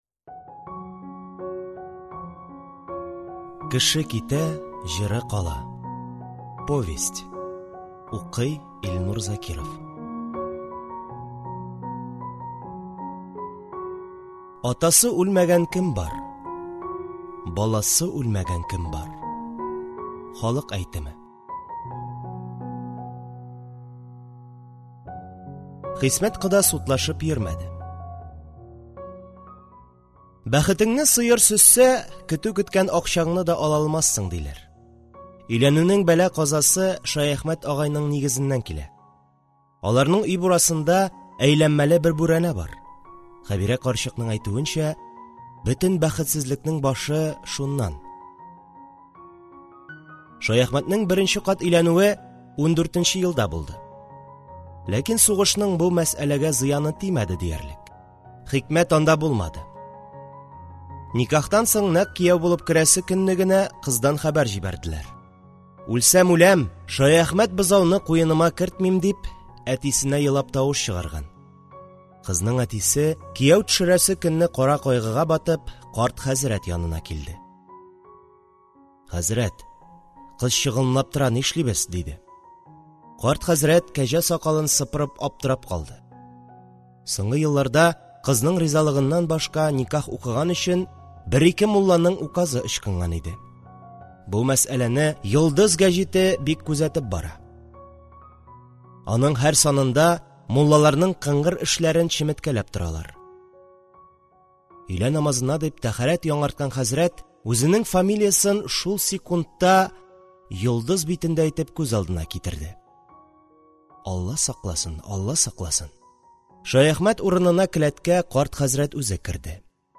Аудиокнига Кеше китә - җыры кала | Библиотека аудиокниг